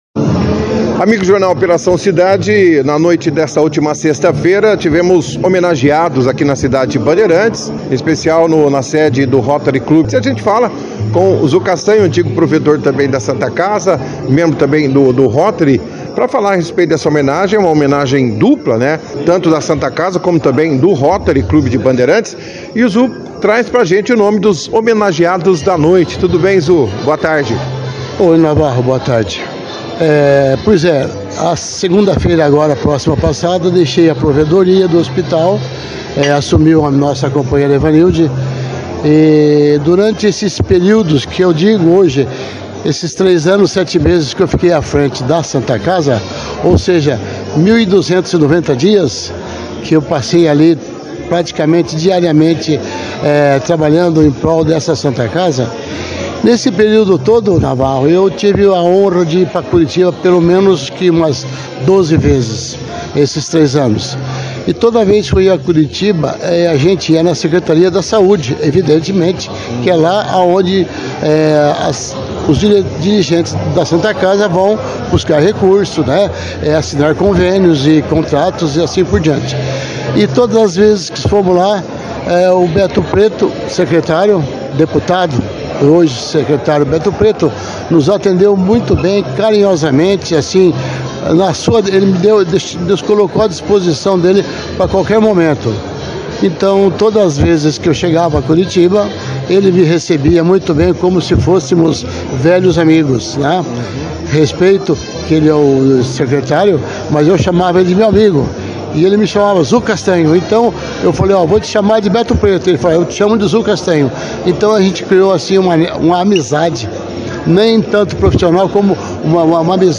A homenagem foi destaque na edição do jornal Operação cidade deste sábado, 26 de setembro